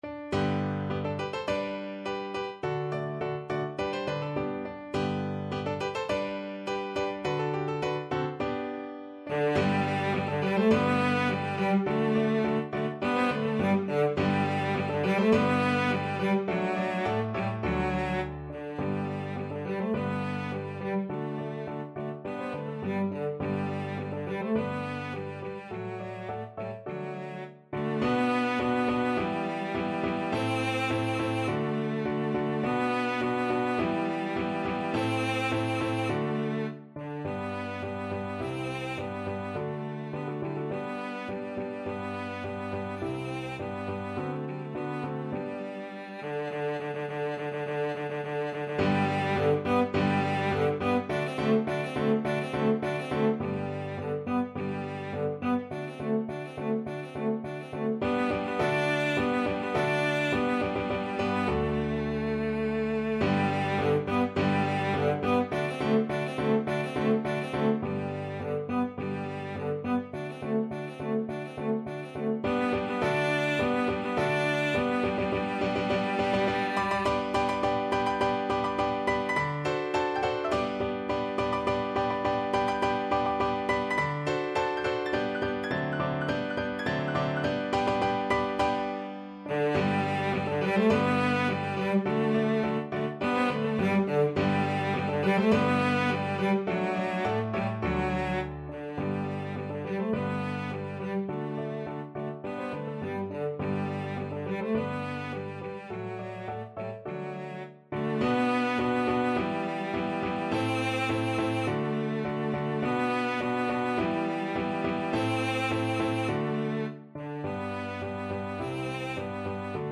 Classical Weber, Carl Maria von Hunter's Chorus from Der Freischutz Cello version
Cello
G major (Sounding Pitch) (View more G major Music for Cello )
~ = 100 Molto vivace =104
2/4 (View more 2/4 Music)
Classical (View more Classical Cello Music)